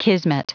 Prononciation du mot kismet en anglais (fichier audio)
Prononciation du mot : kismet